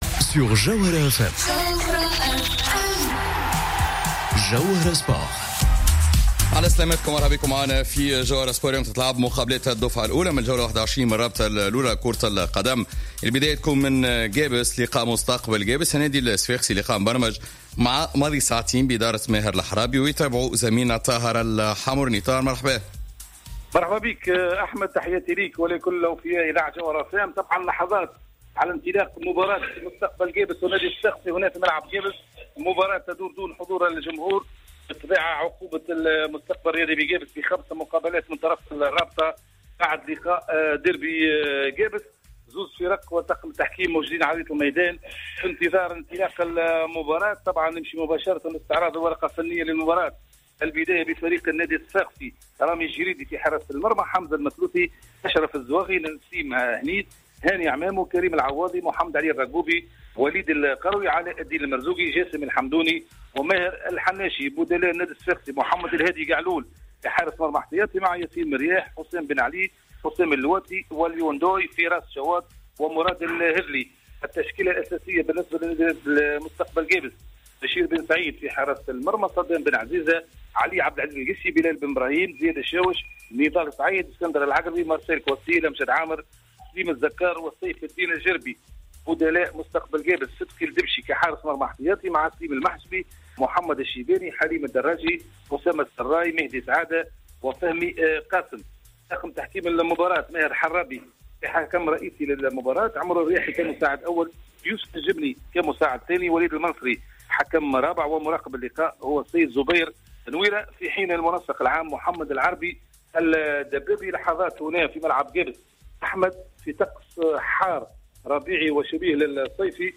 متابعة مباشرة لمقابلات الدفعة الأولى من الجولة 21 من بطولة الرابطة الأولى